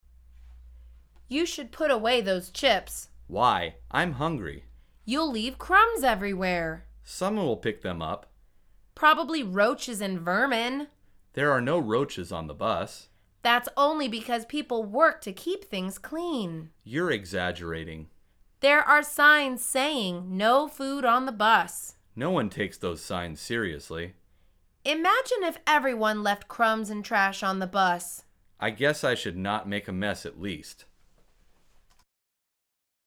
مجموعه مکالمات ساده و آسان انگلیسی – درس شماره نوزدهم از فصل سوار شدن به اتوبوس: غذا خوردن تو اتوبوس